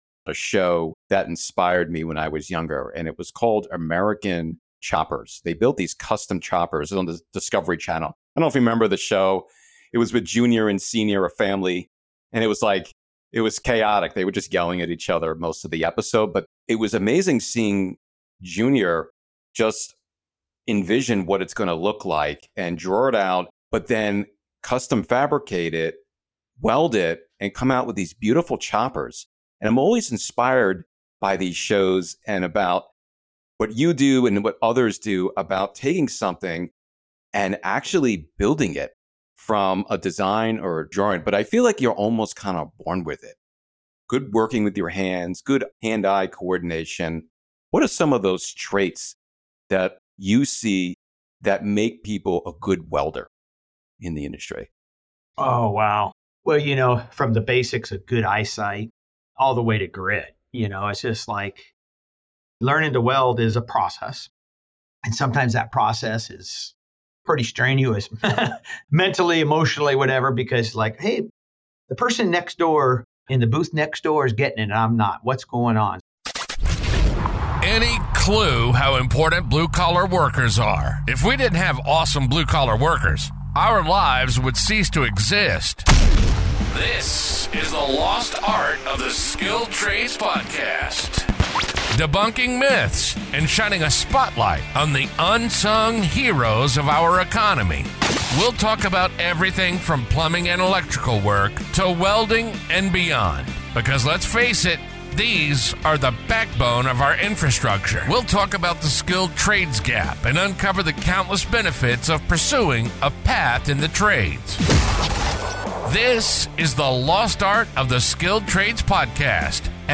Don't miss this inspiring conversation!